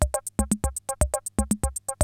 CR-68 LOOPS2 1.wav